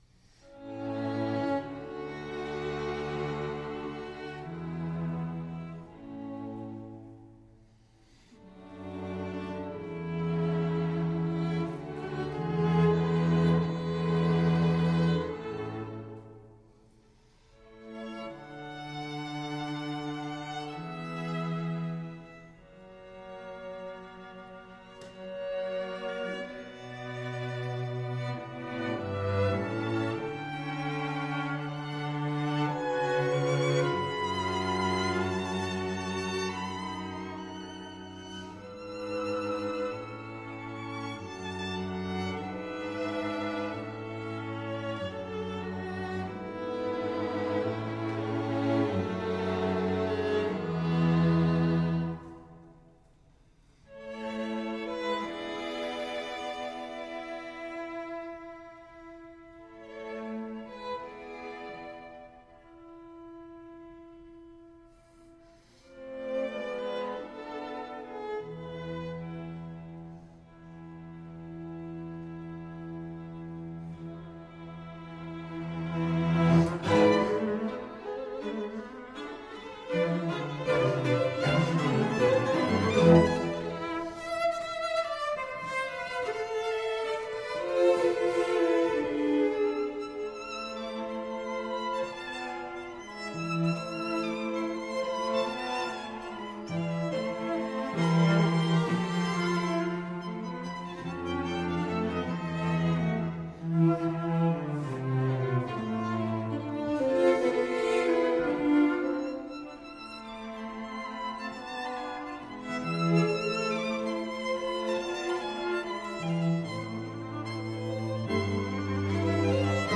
String Quartet
Style: Classical
Audio: Boston - Isabella Stewart Gardner Museum
Audio: Borromeo String Quartet